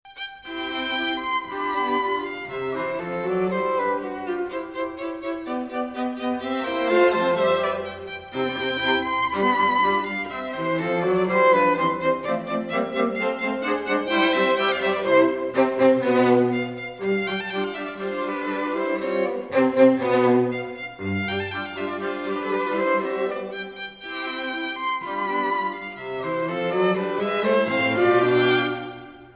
Click the blue titles below to hear Cotswold Ensemble string quartet players performing.